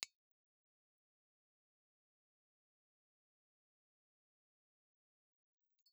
Piezoelectric Crystal
Omnidirectional
Impulse Response file of the Aiwa M18 crystal microphone.
Aiwa_M18_IR.wav